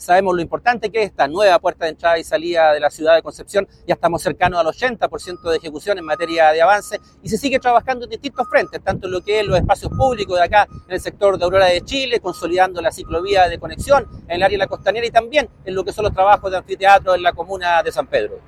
Al respecto, el seremi de Obras Públicas, Hugo Cautivo, destacó la relevancia de esta fase del proyecto y la continuidad de los trabajos en distintos frentes de la ciudad.
cuna-seremi-obras-publicas.mp3